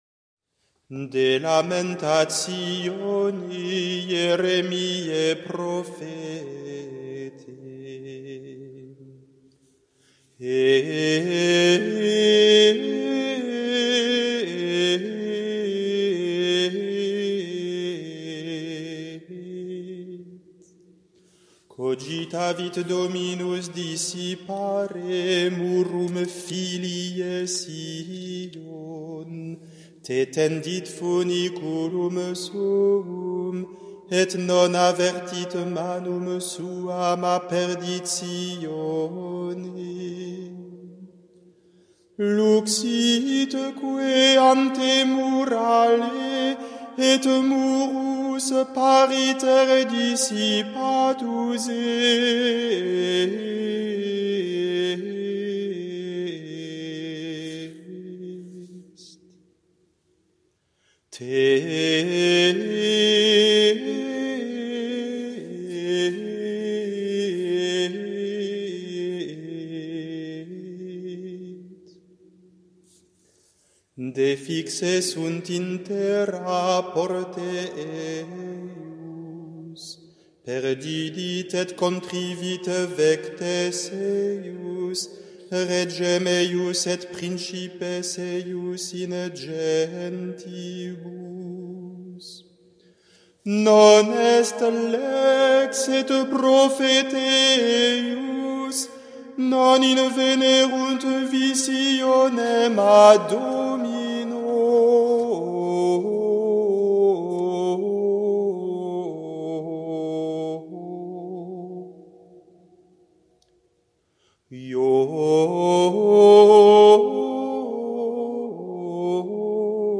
Le chant d’une lamentation (4/9)